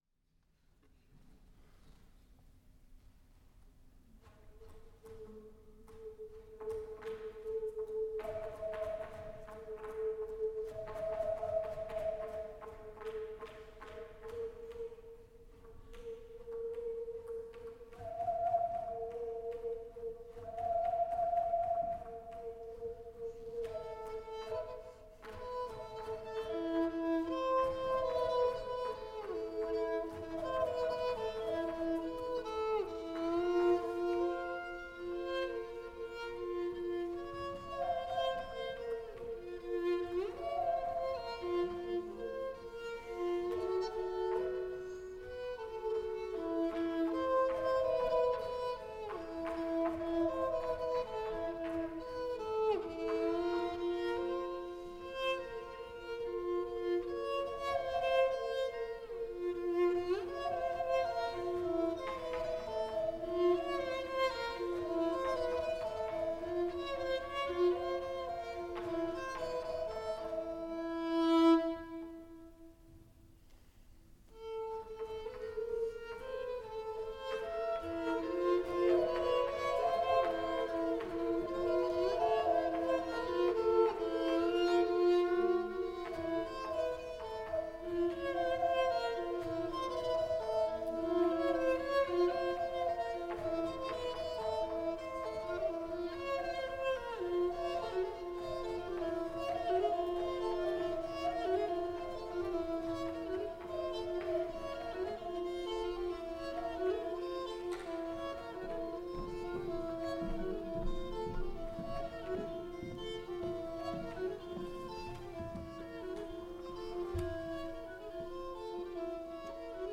FOR STRING QUARTET
violin
viola
cello